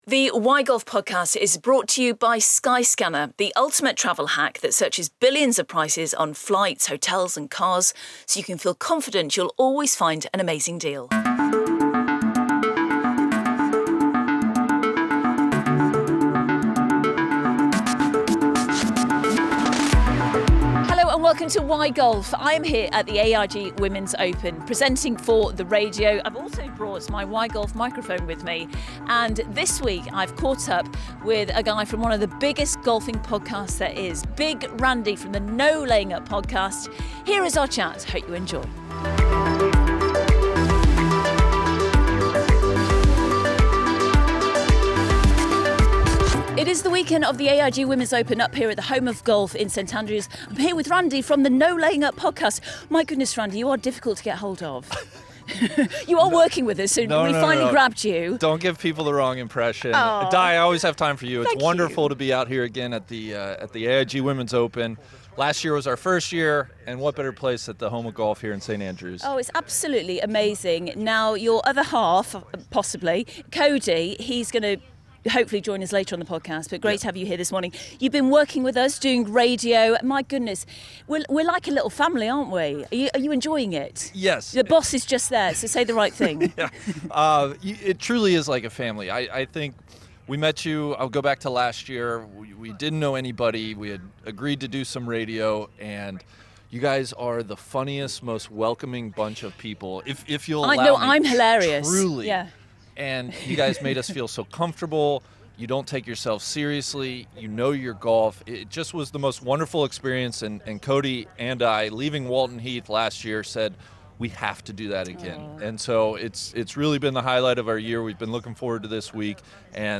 Live from the AIG Women’s Open
We’ve travelled to the home of golf, St Andrews, for this episode of Why Golf!